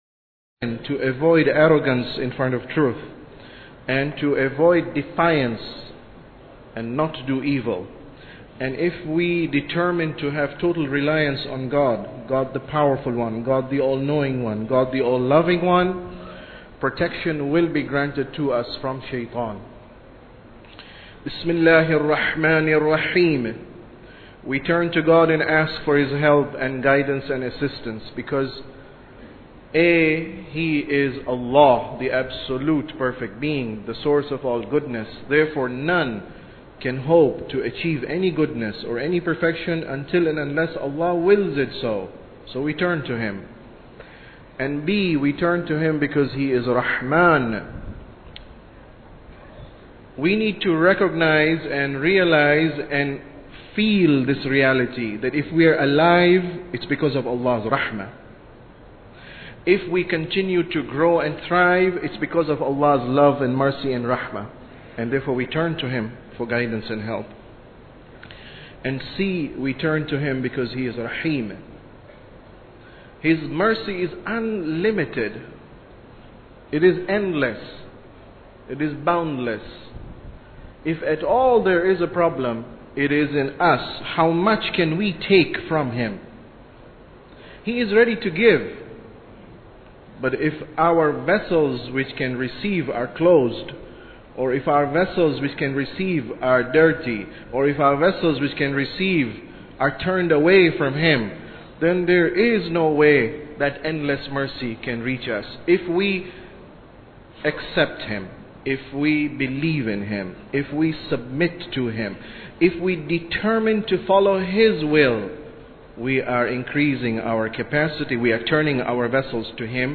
Sermon About Tawheed 11